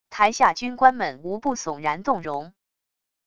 台下军官们无不耸然动容wav音频生成系统WAV Audio Player